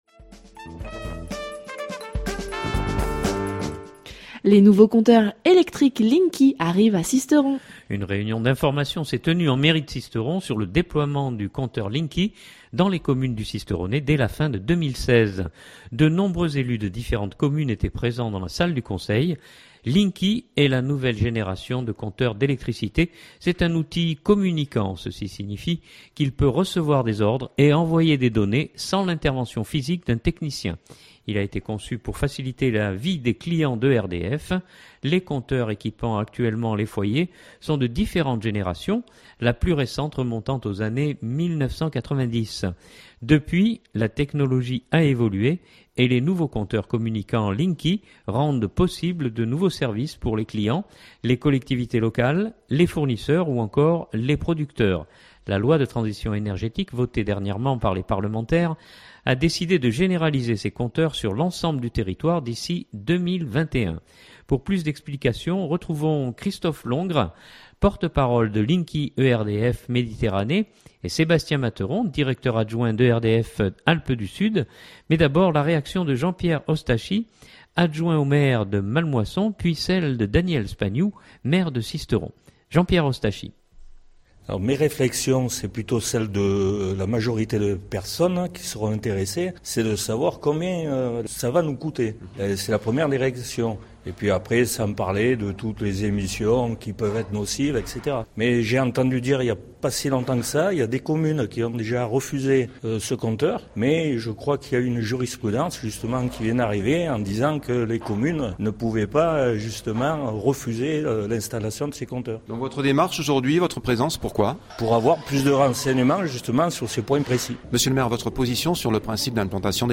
Une réunion d’information s’est tenue en Mairie de Sisteron sur le déploiement du compteur Linky dans les communes du Sisteronais dès la fin de 2016.